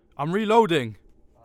Combat Dialogue
Marcel reloading.wav